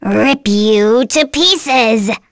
Nia-Vox_Skill6.wav